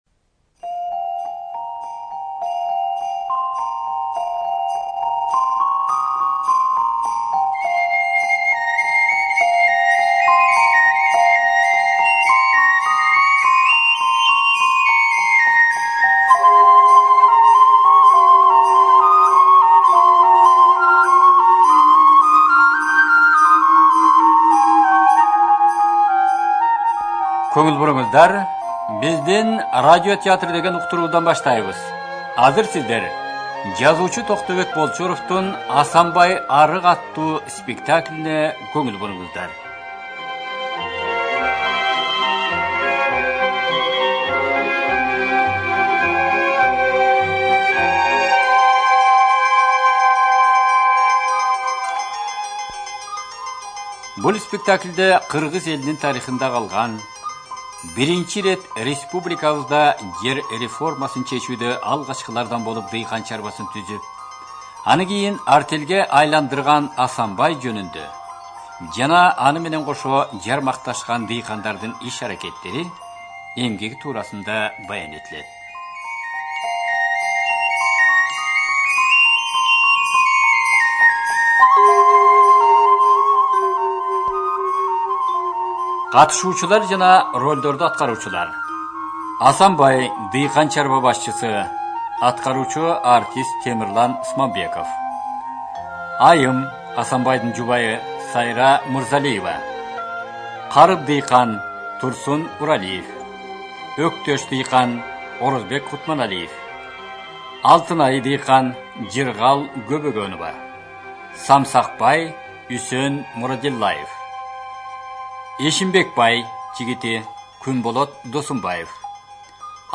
ЖанрРадиоспектакли на кыргызском языке